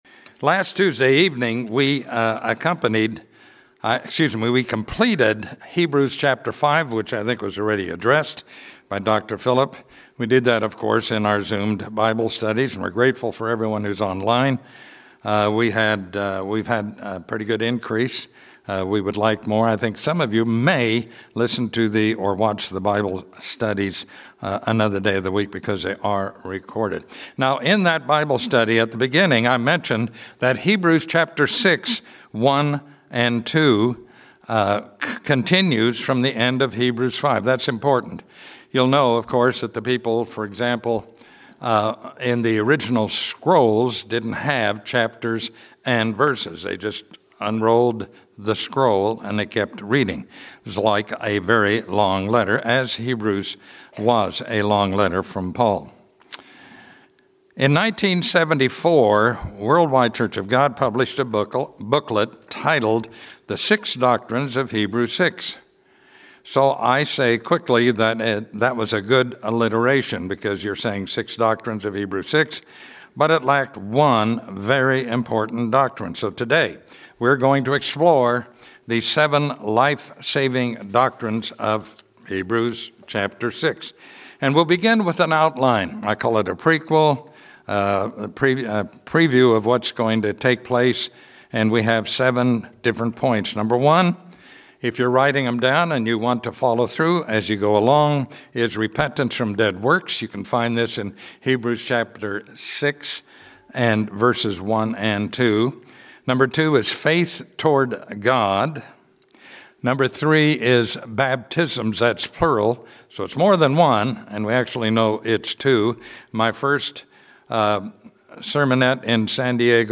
Sermons
Given in Atlanta, GA